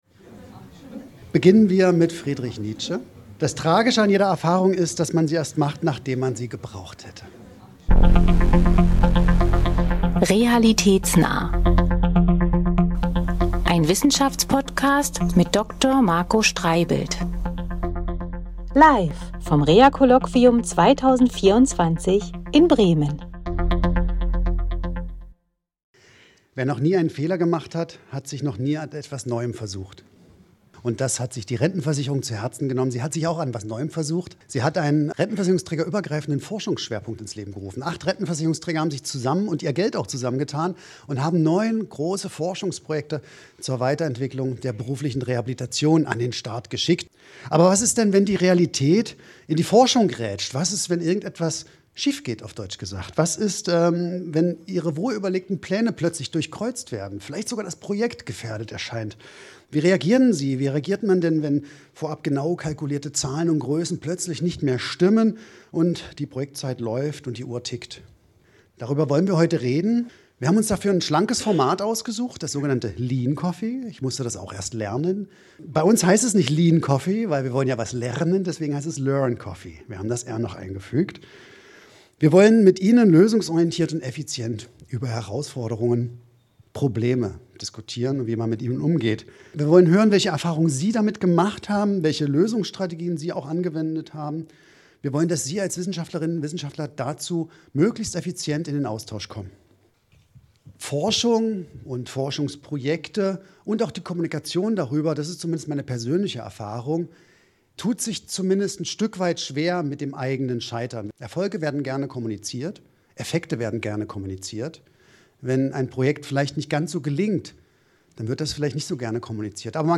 Das Publikum stimmte ab, worüber lösungsorientiert und effizient diskutiert werden sollte. Hören Sie in dieser Ausgabe, wie es gelingen kann, die Rekrutierungszahlen von Teilnehmenden bei Forschungsprojekten zu erreichen.